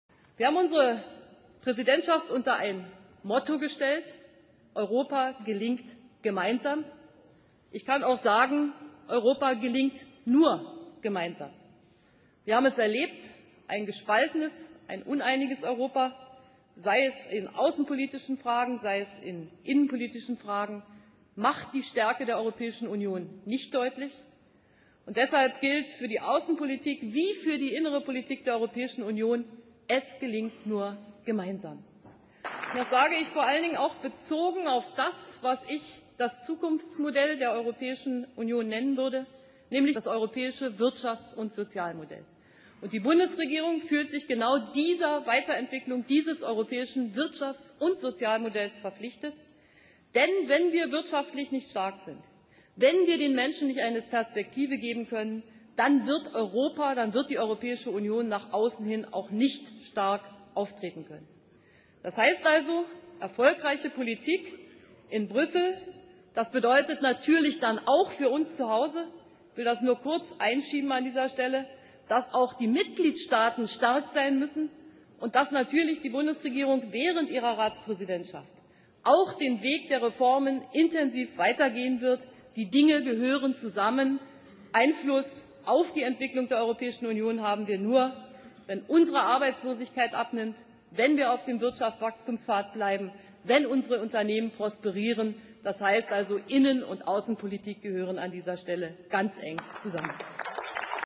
Im Deutschen Bundestag hat Bundeskanzlerin Angela Merkel ihre Ziele für die deutschen Präsidentschaften im Rat der Europäischen Union und bei den G8-Staaten erläutert. Sie rief Koalition, Opposition und Länder zur Unterstützung auf.